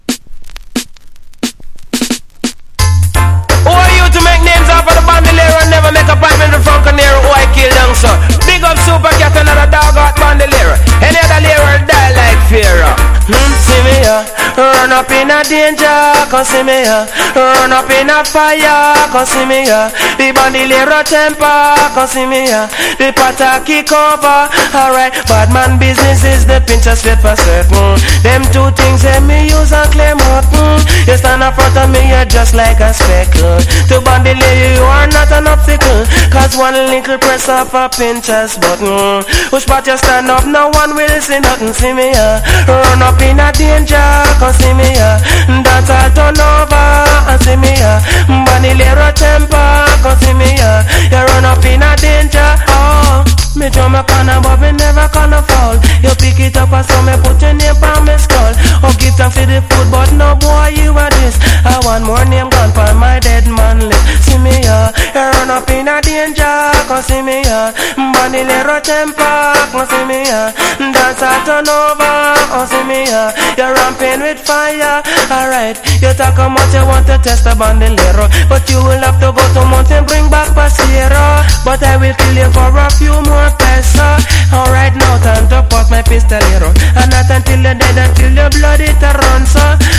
• REGGAE-SKA
# DANCE HALL